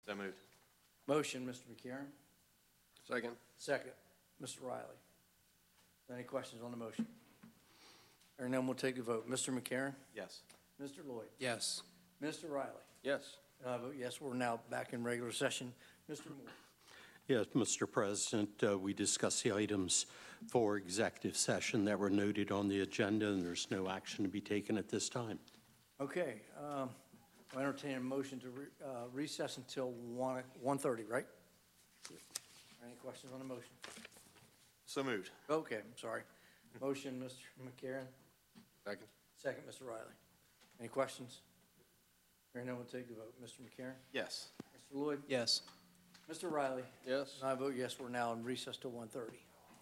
County Council Meeting | Sussex County
Meeting location: Council Chambers, Sussex County Administrative Office Building, 2 The Circle, Georgetown